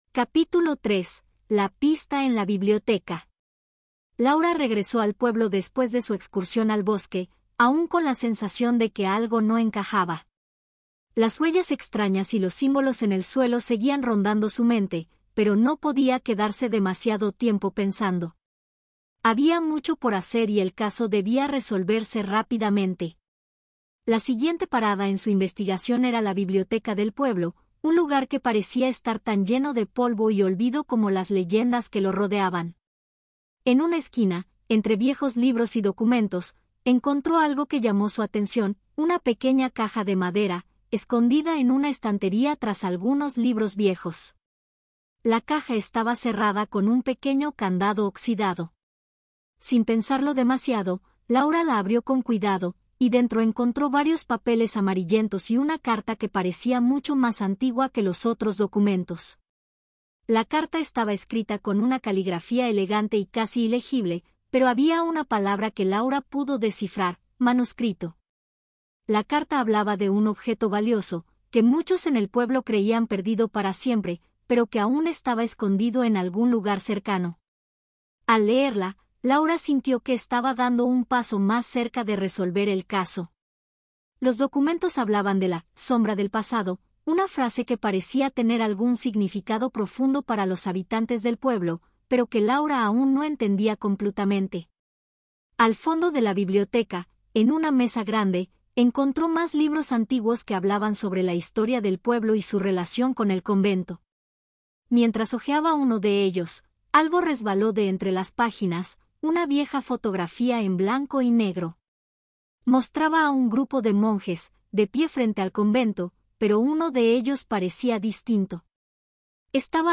AudioBook El misterio del manuscrito B2-C1 - Hola Mundo